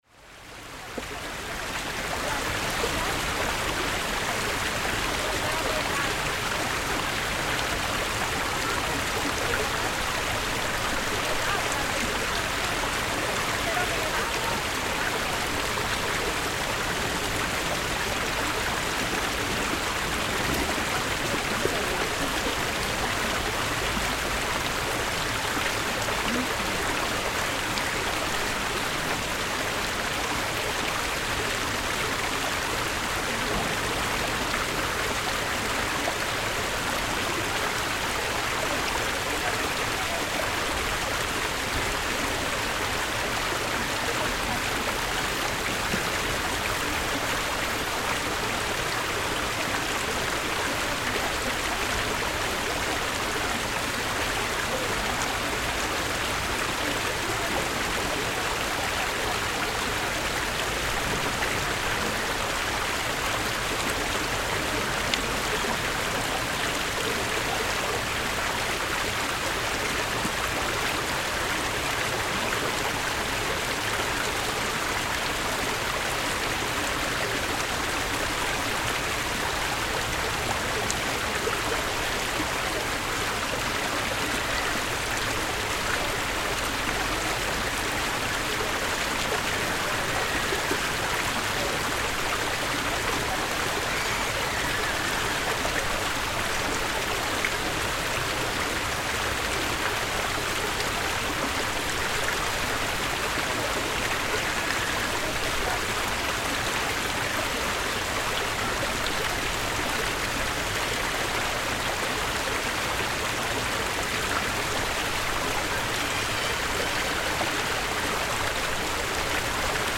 Water feature in Cathedral Gardens, Manchester
Outside the National Football Museum, a modern water feature recorded during a tranquil lunchtime in Manchester.